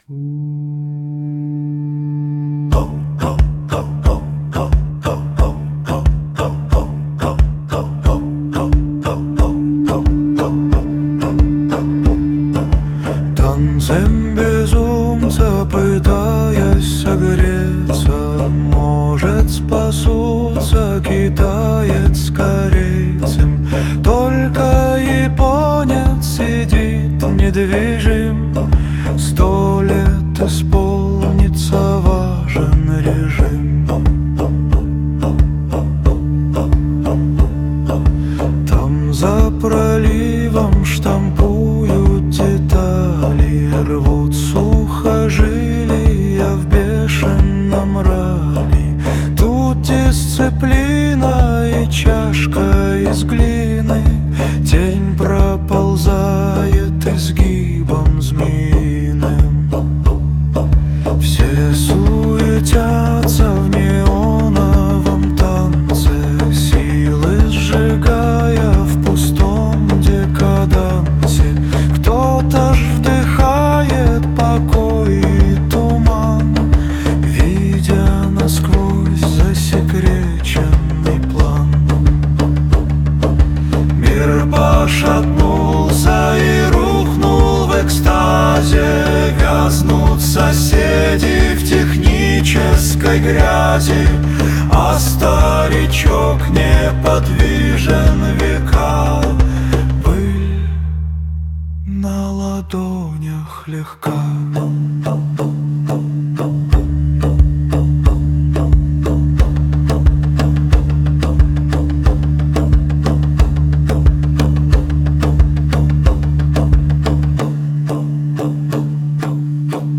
• Жанр: Фолк